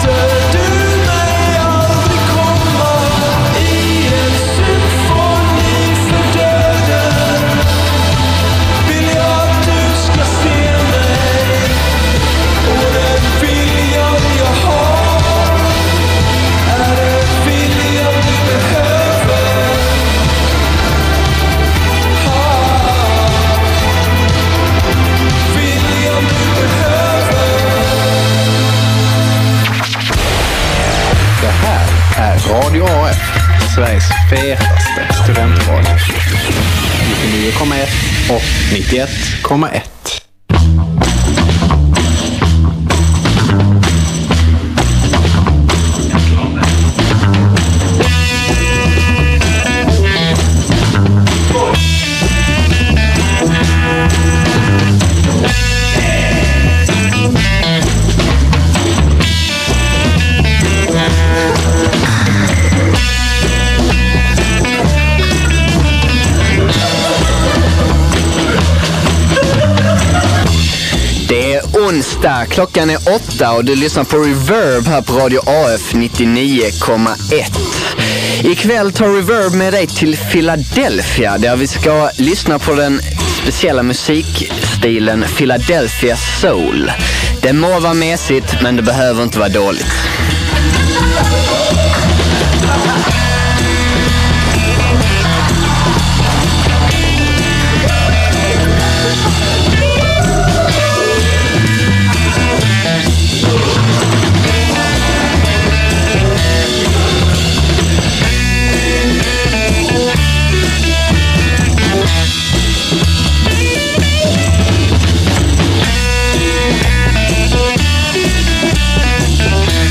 Nu är vi i Philadelphia, där soulen är lite lugnare, lite snällare och lite - mesigare?